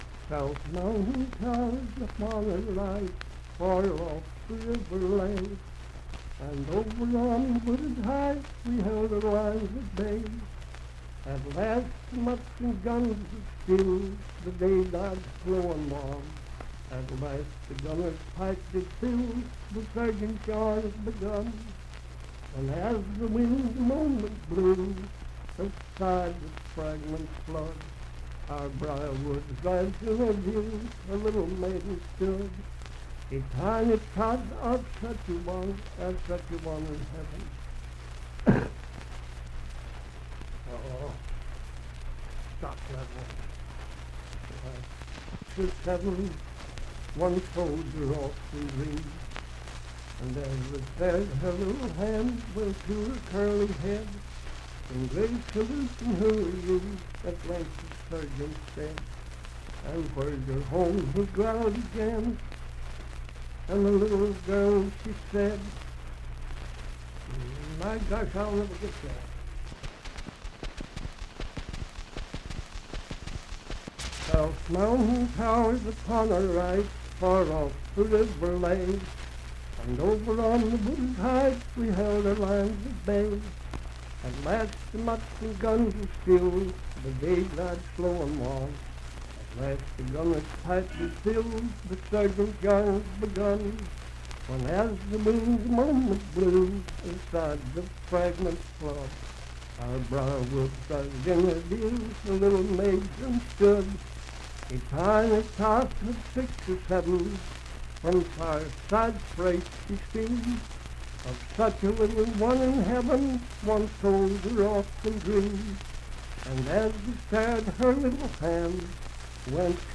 Unaccompanied vocal music
in Mount Storm, W.V.
Verse-refrain 9(4).
Voice (sung)